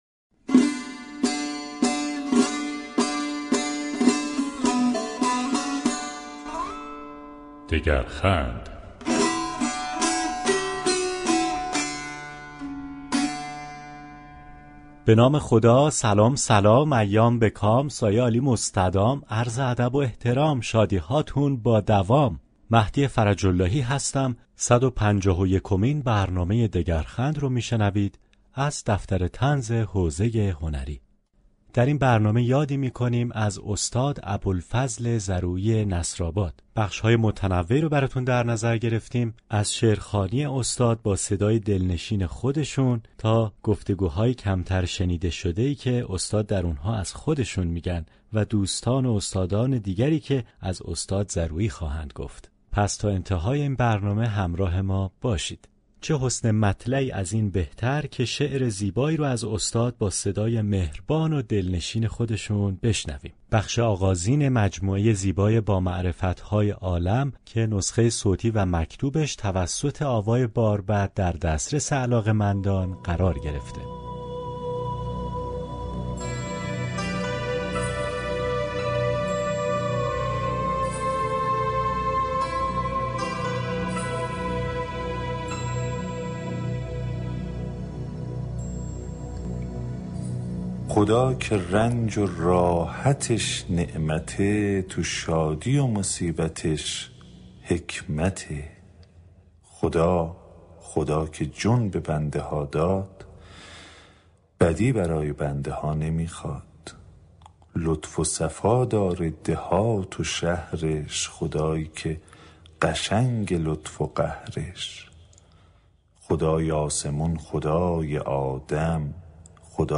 در ادامه یكی از حكایت‌های كتاب غلاغه به خونش نرسید به صورت نمایشی اجرا شده‌است.